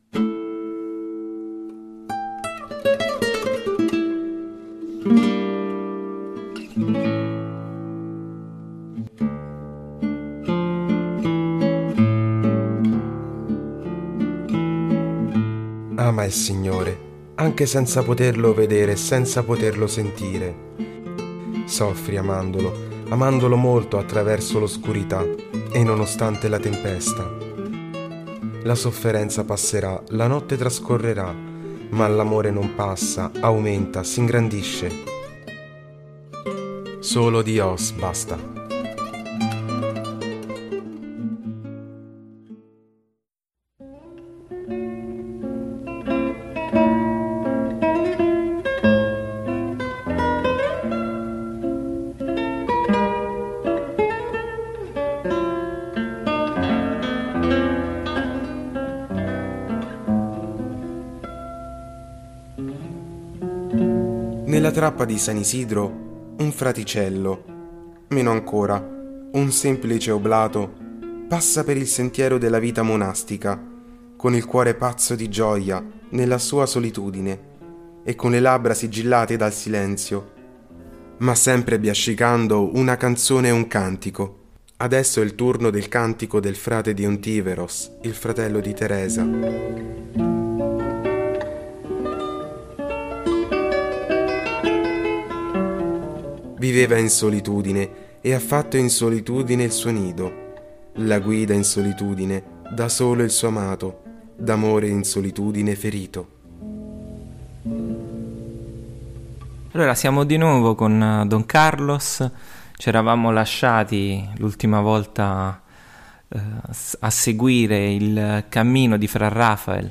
SAN-RAFAEL-TERZA-PUNTATA-DEF-CON-EFFETTI.mp3